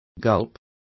Complete with pronunciation of the translation of gulp.